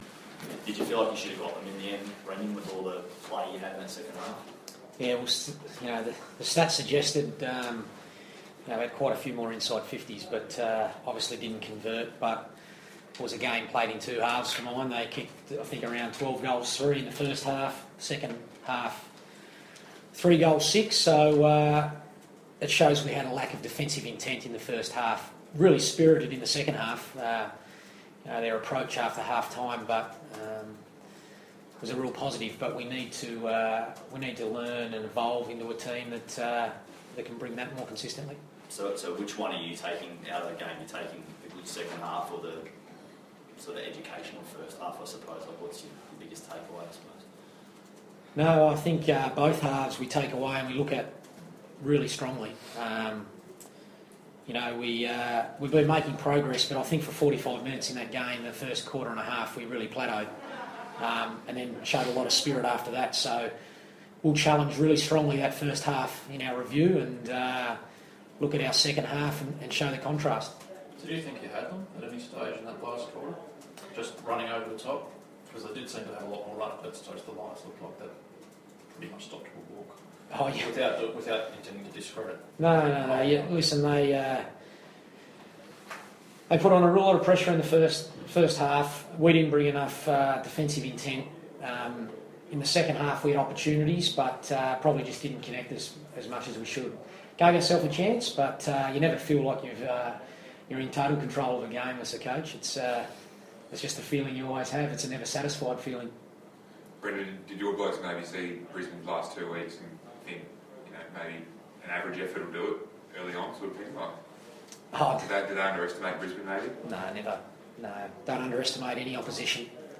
Carlton coach Brendon Bolton fronts the media after the Blues' four-point loss to Brisbane at The Gabba.